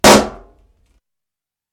Balloon-Burst-02
balloon burst pop sound effect free sound royalty free Sound Effects